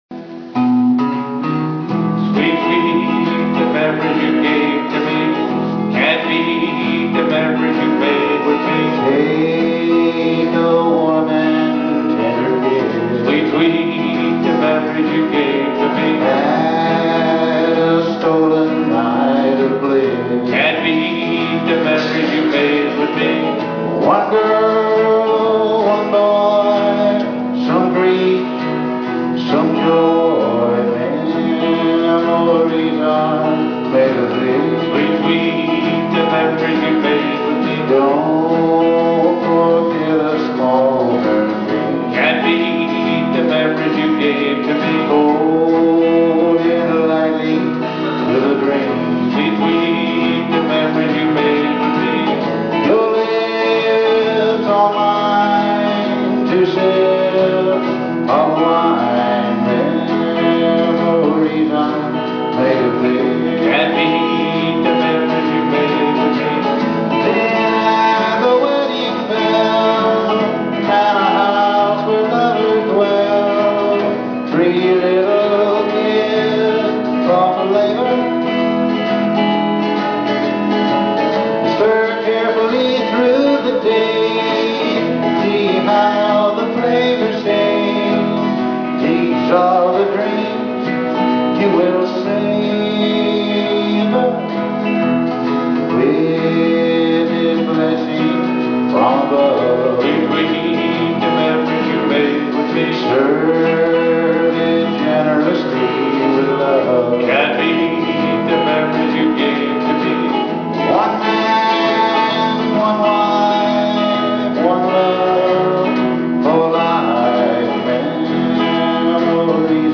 Classic Country & Oldies From the 50's 60's & 70's